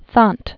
(thänt, thănt), U 1909-1974.